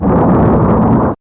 ThndrCrk.wav